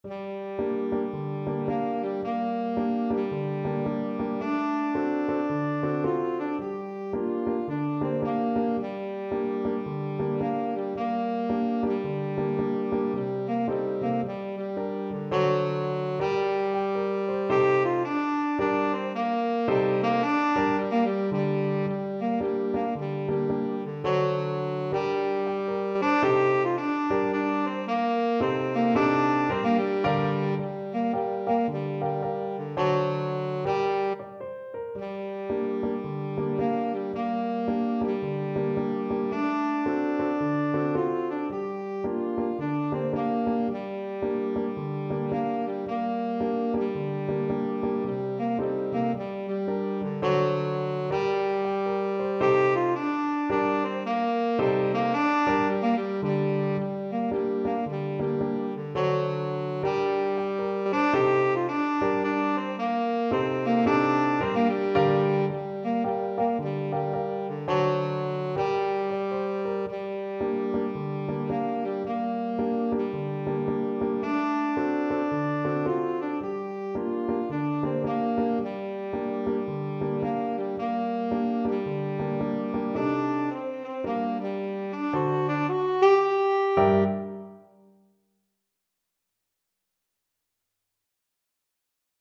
4/4 (View more 4/4 Music)
Moderate Gospel , Swung = c.110
Gospel & Spiritual Tenor Sax